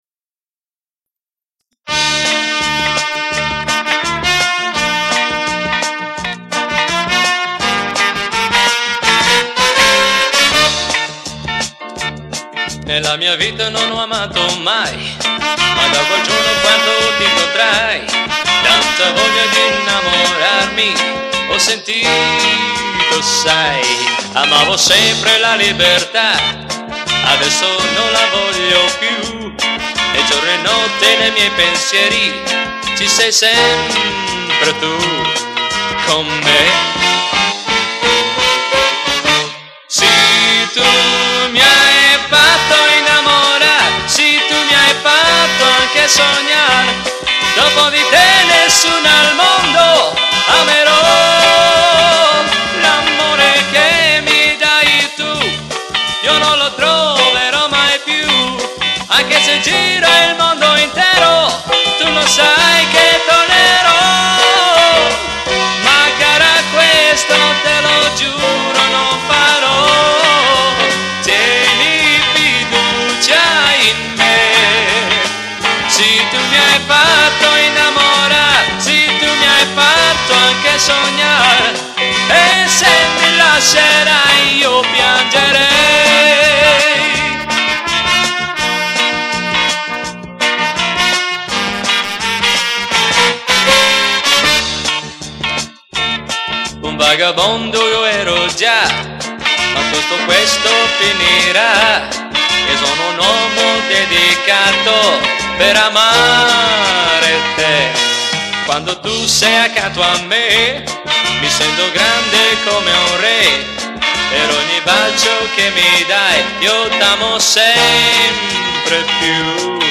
Piano Accordion, Organ, Strings & Electric Piano
Electric & Acoustic Guitars
Electric Bass
Drums
Brass Section
BACKGROUND VOCALS
Recorded at Riversound Recordings, Sydney